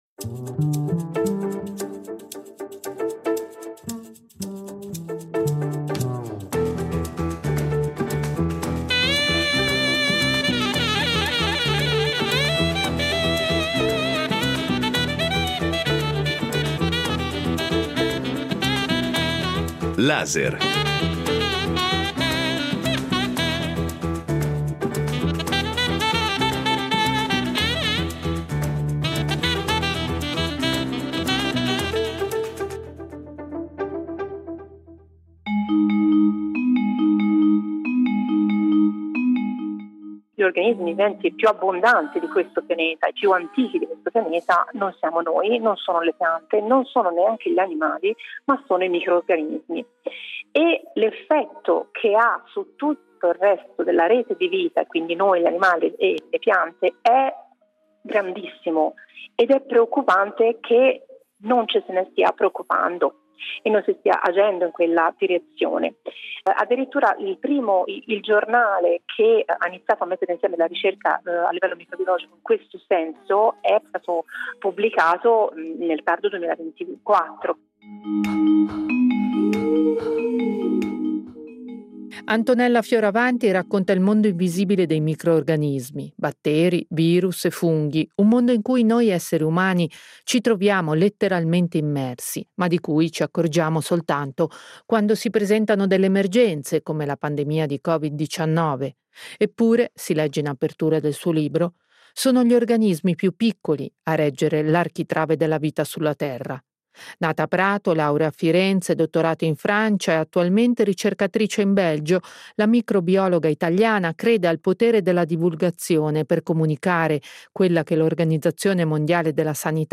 A colloquio